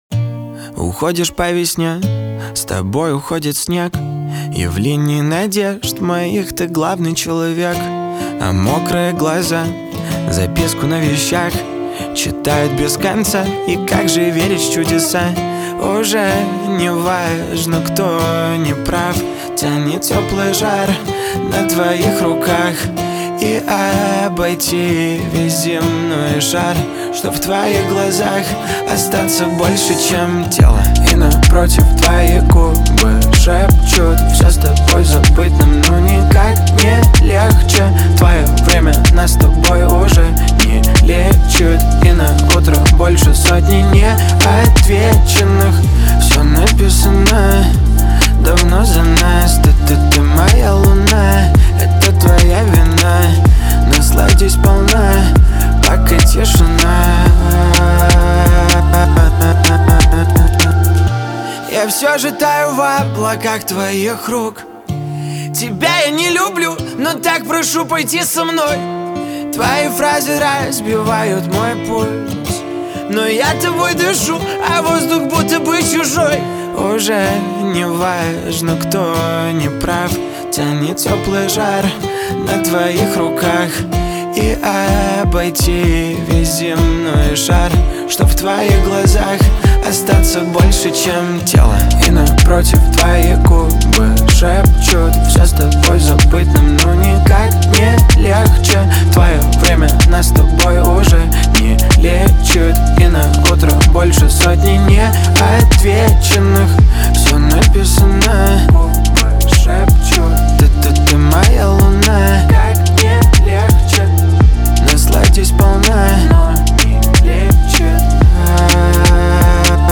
Жанр:Русские новинки / OSTСаундтреки